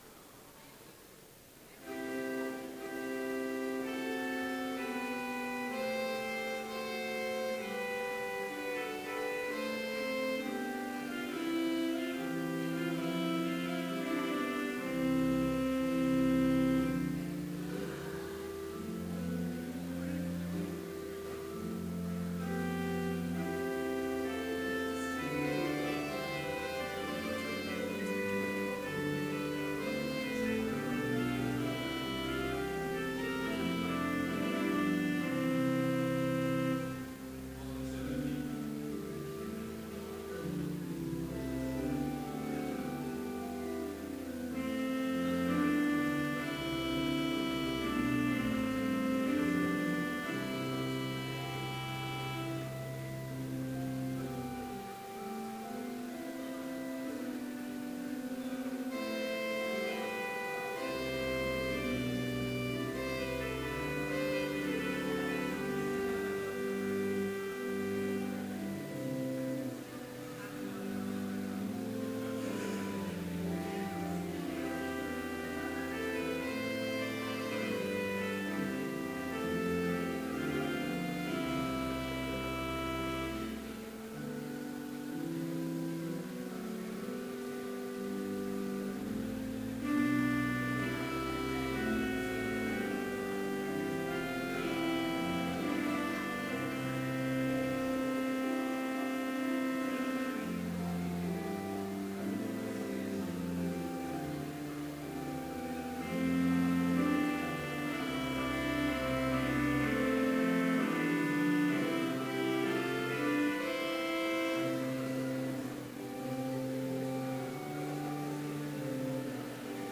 Complete service audio for Chapel - November 5, 2015